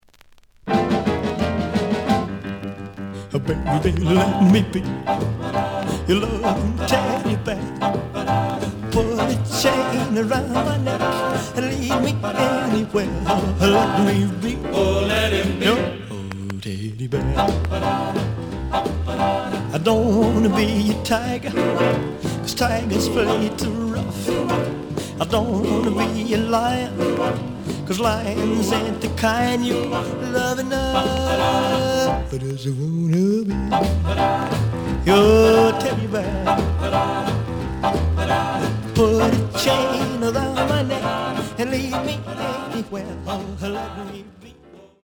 試聴は実際のレコードから録音しています。
The audio sample is recorded from the actual item.
●Genre: Rhythm And Blues / Rock 'n' Roll